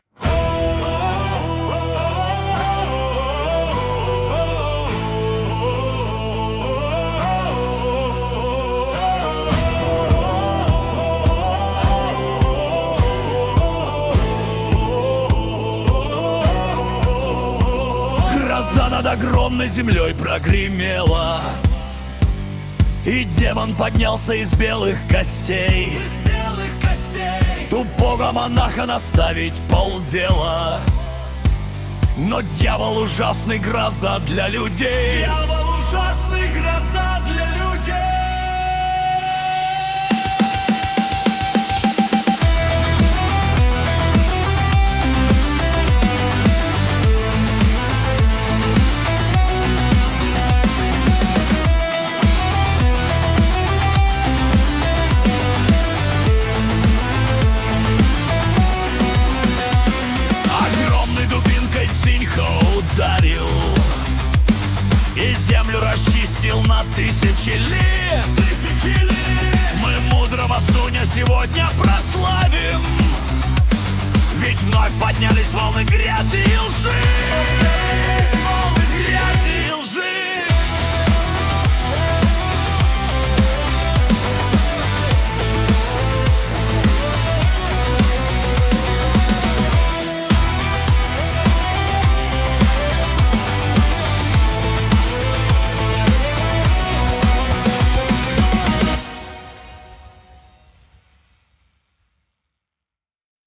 Первый-ответ-товарищу-Го-Можо-male-pop-rock.opus